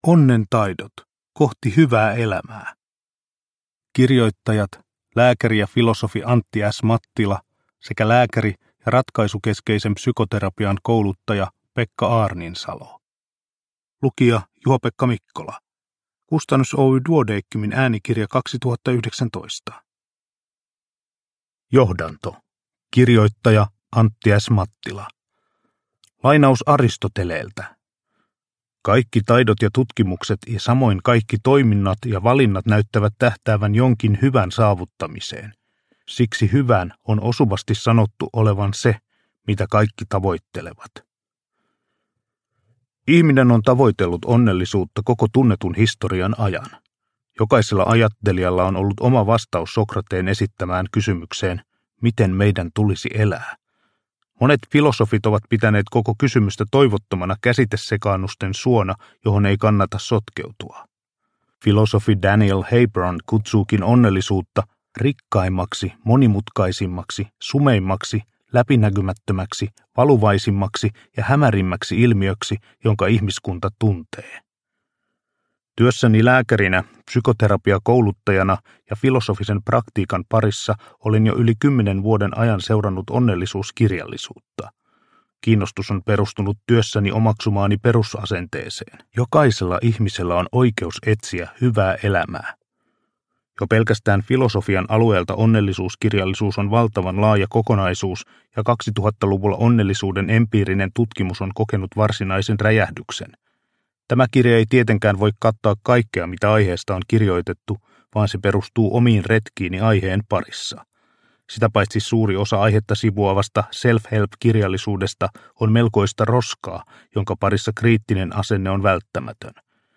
Onnentaidot – Ljudbok – Laddas ner